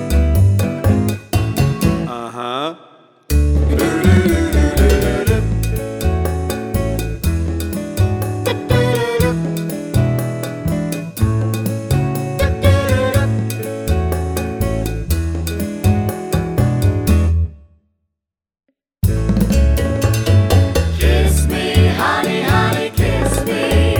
no Backing Vocals Jazz / Swing 2:24 Buy £1.50